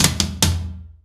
ROOM TOM4C.wav